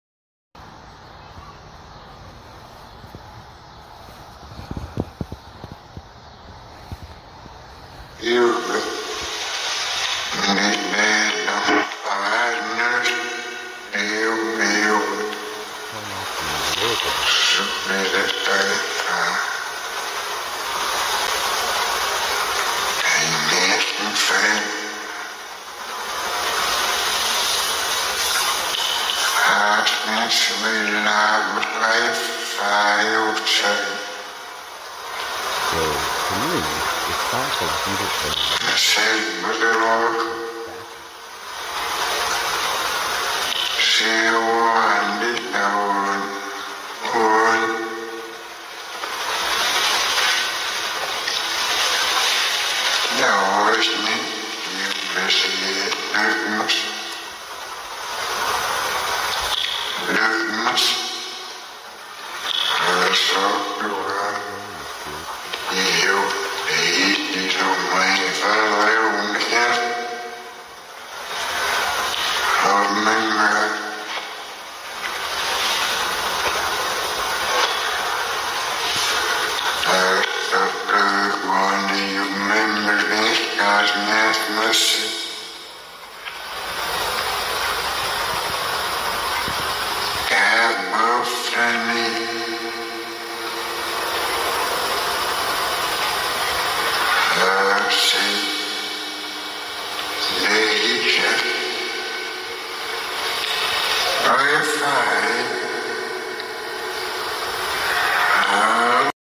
Trying out The Miracle Box App. Everything sounds backwards!!!